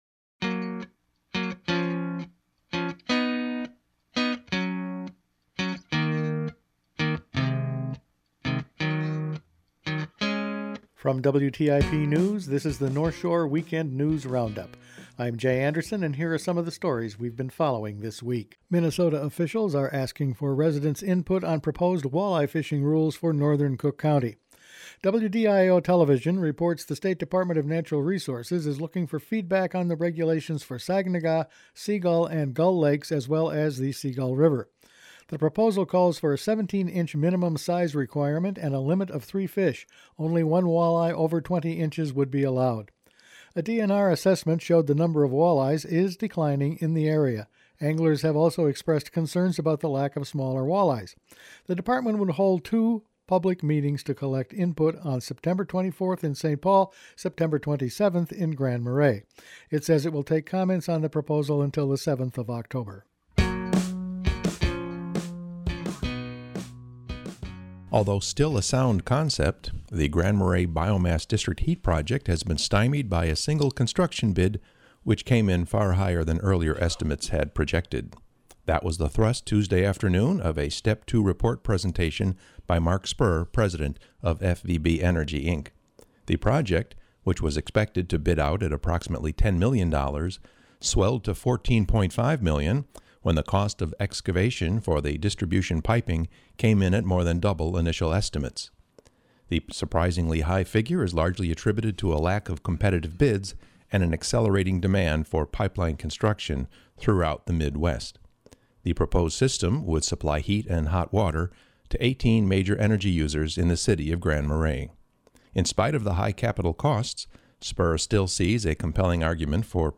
Attachment Size FinalCut_082214.mp3 20.08 MB Each week the WTIP news department puts together a roundup of the weeks top news stories. Walleye regulations for some border lake, mining news and the district heating plant…all this and more in this week’s news.